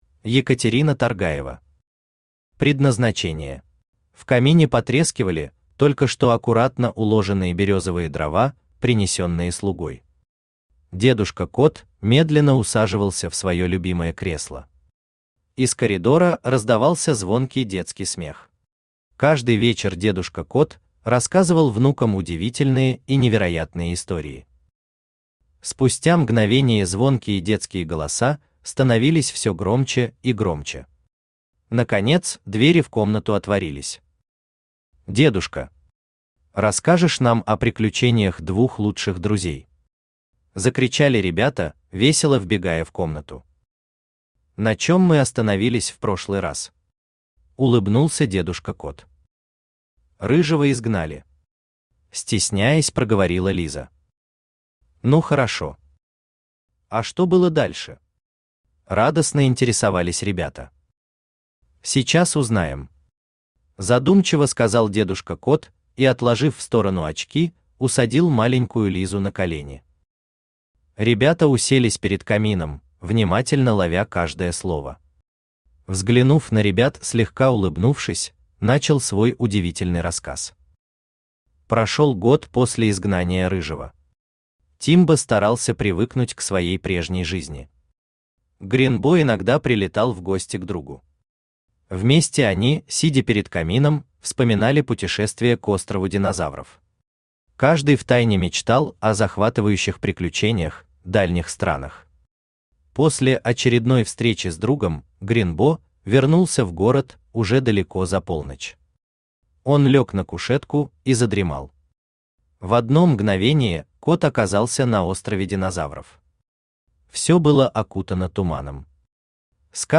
Aудиокнига Предназначение Автор Екатерина Таргаева Читает аудиокнигу Авточтец ЛитРес.